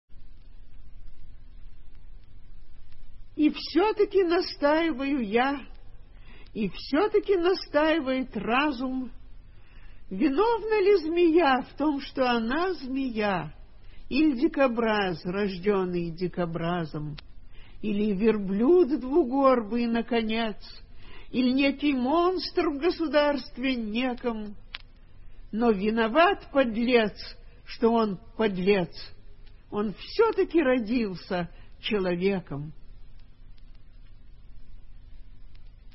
1. «Маргарита Алигер – И все-таки настаиваю я… (читает автор)» /